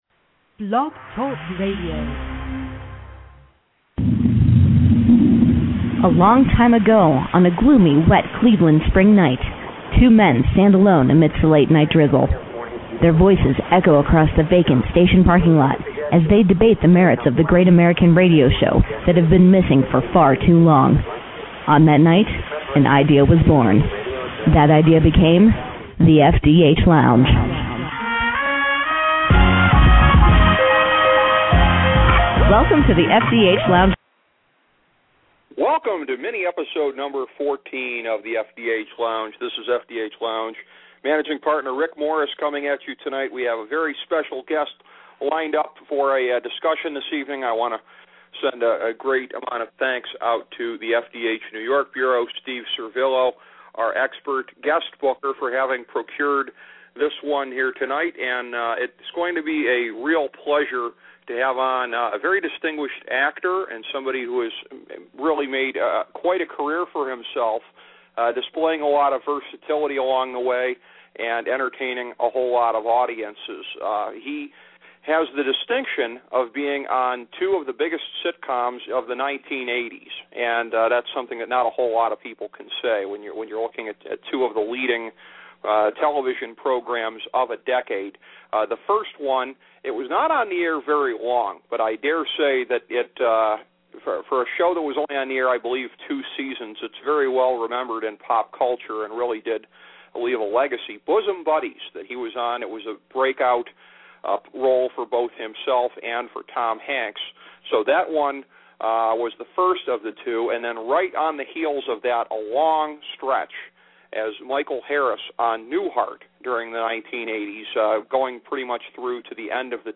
A coversation with actor Peter Scolari
The FDH Lounge speaks with accomplished actor and one of the top sitcom stars of the 1980s, Peter Scolari.